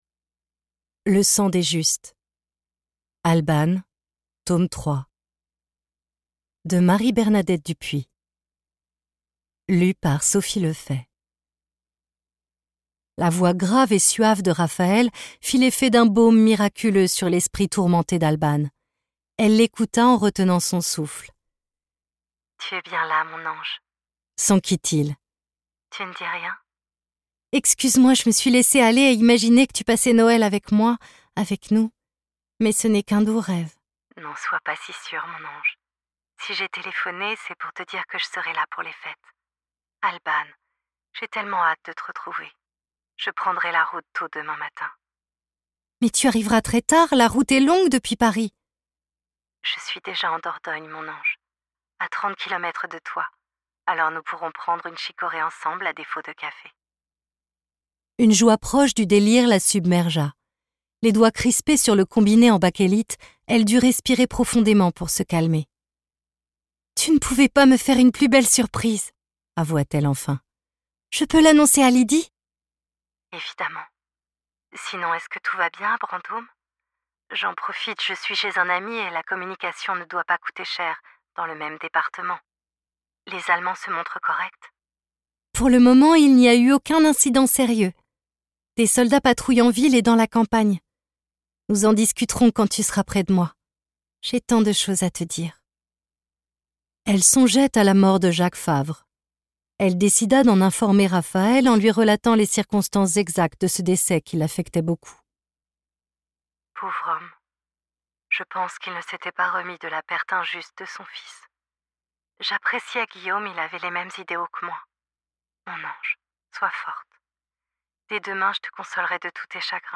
0% Extrait gratuit Albane